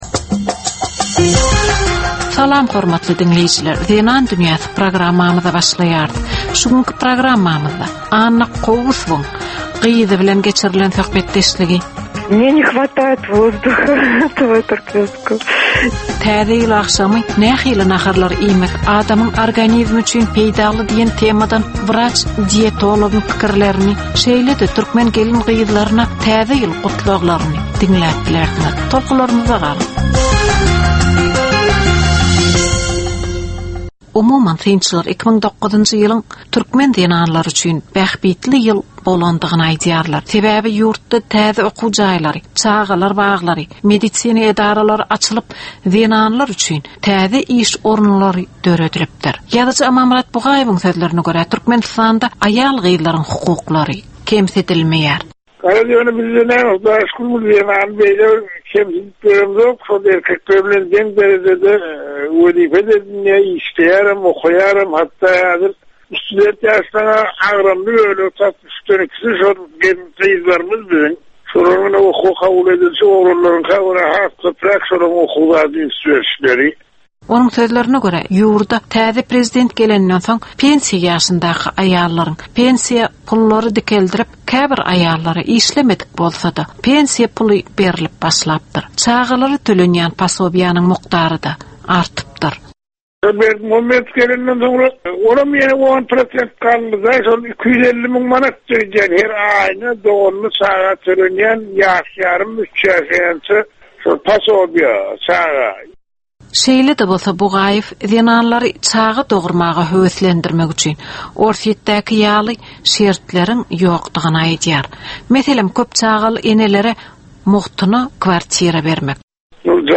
Türkmen we halkara aýal-gyzlarynyň durmuşyna degişli derwaýys meselelere we täzeliklere bagyşlanylyp taýýarlanylýan 15 minutlyk ýörite gepleşik. Bu gepleşikde aýal-gyzlaryn durmuşyna degişli maglumatlar, synlar, bu meseleler boýunça synçylaryň we bilermenleriň pikirleri, teklipleri we diskussiýalary berilýär.